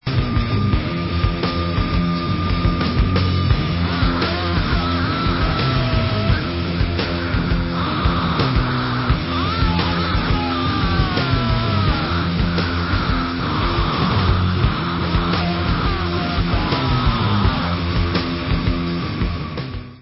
Rock/Alternative Metal